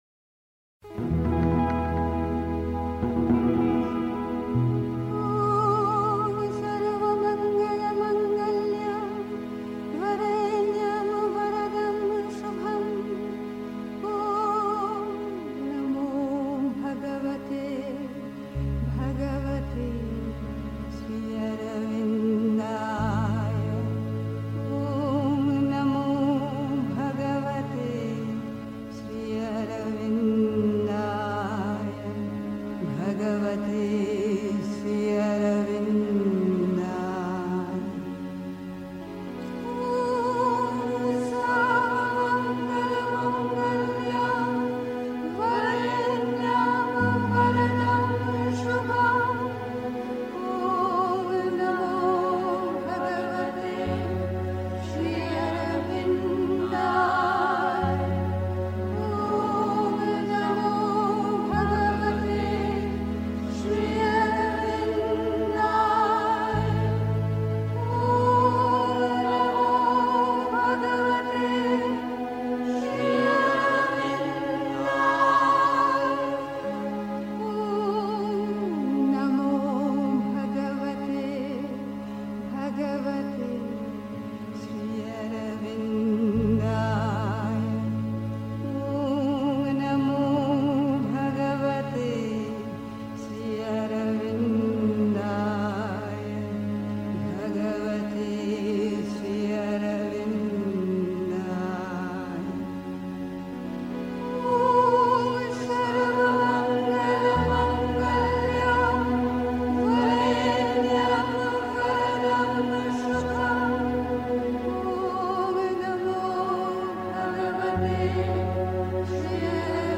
Januar 1961) 3. Zwölf Minuten Stille.